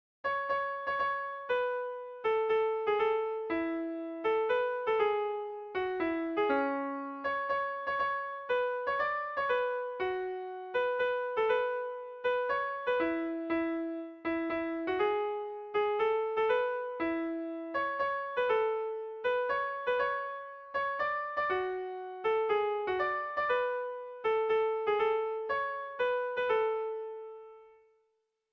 Sentimenduzkoa
Etxahun Iruri
Zortziko handia (hg) / Lau puntuko handia (ip)